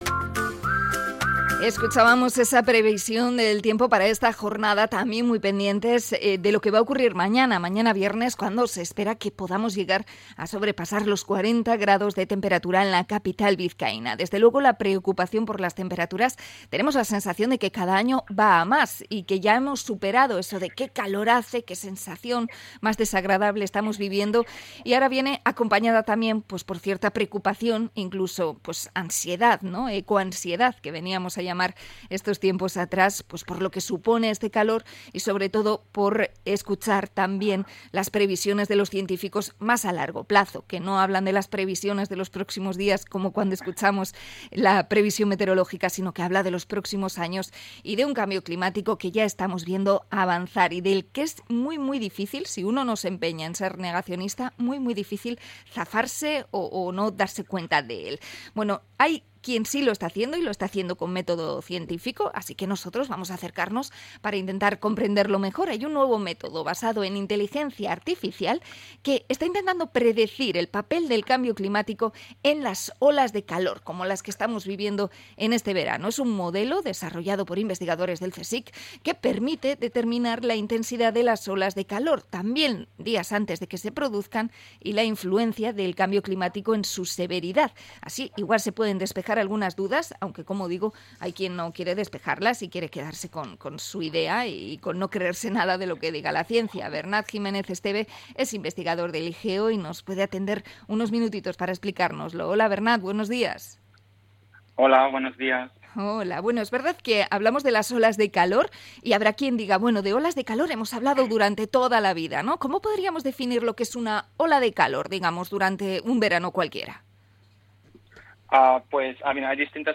Entrevista a investigador del CSIC por las olas de calor